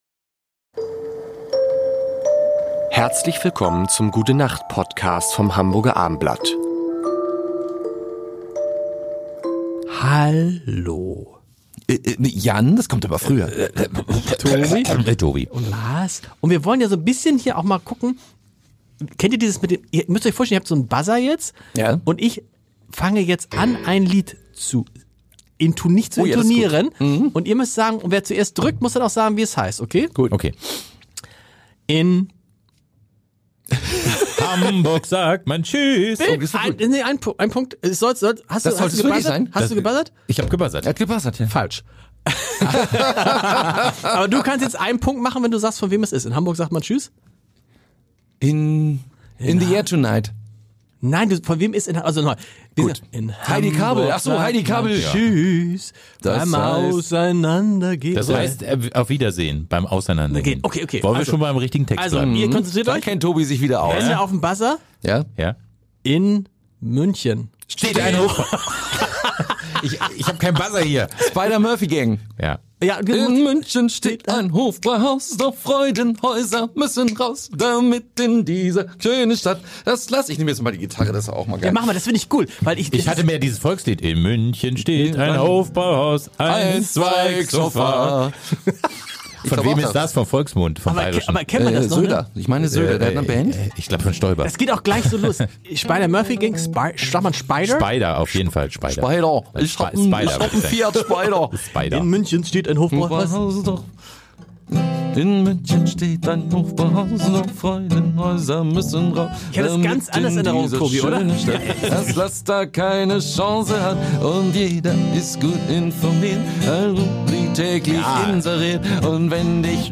Songtexte raten und singen